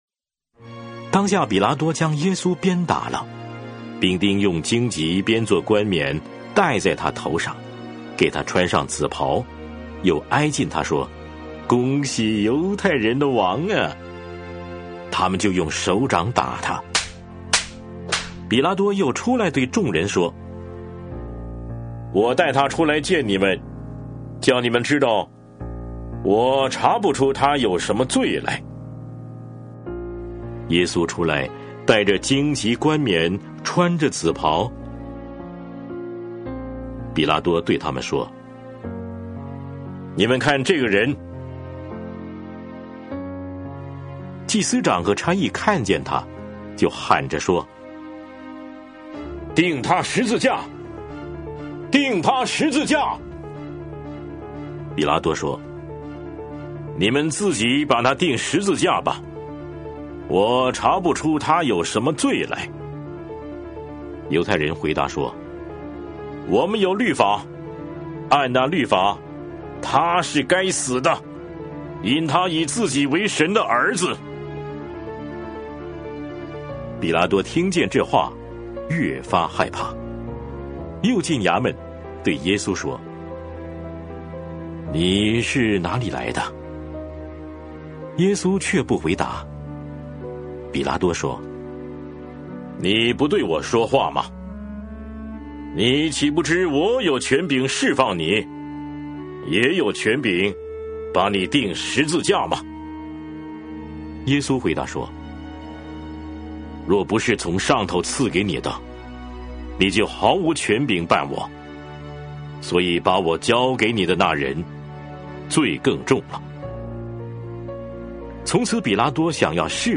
每日读经 | 约翰福音19章